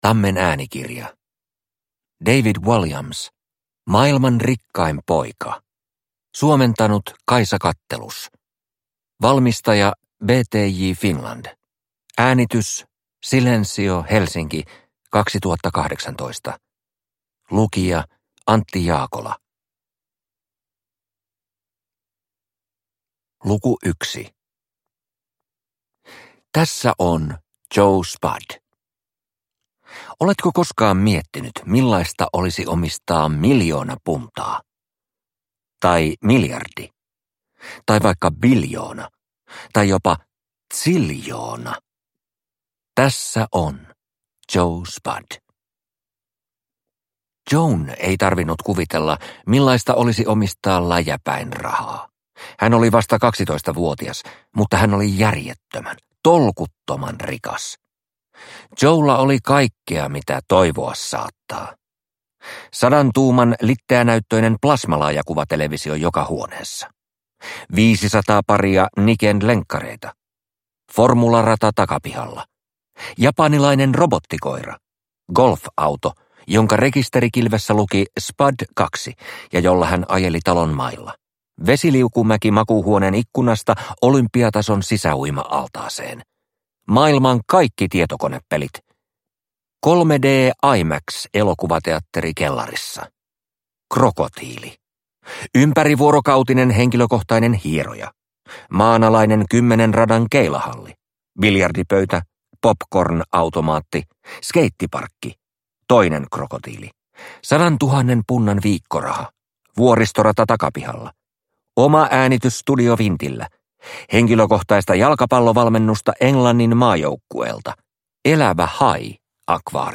Maailman rikkain poika – Ljudbok – Laddas ner